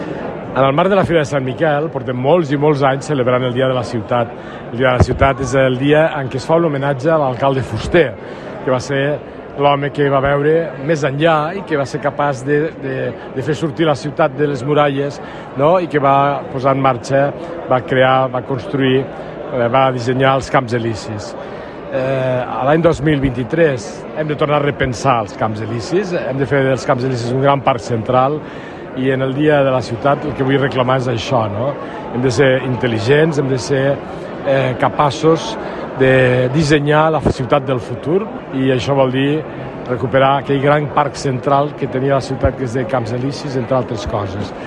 TALL DE VEU ALCALDE LARROSA